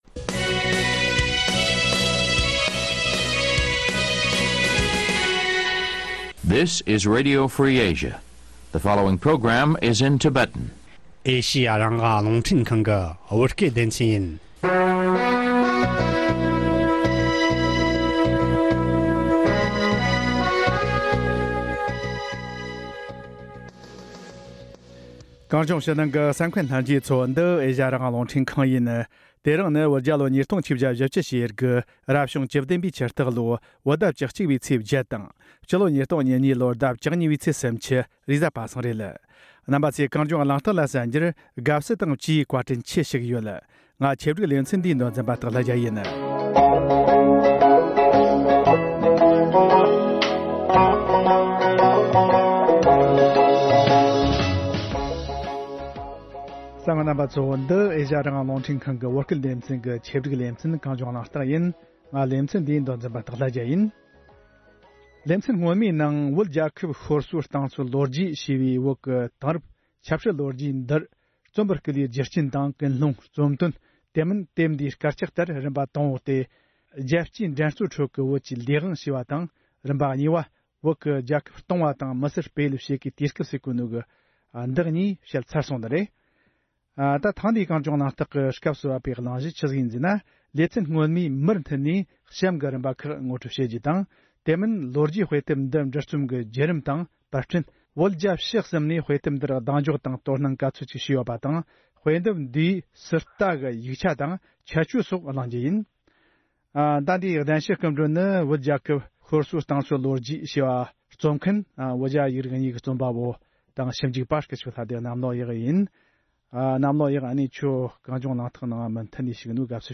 བགྲོ་གླེང་བྱས་པར་གསན་རོགས་གནོངས།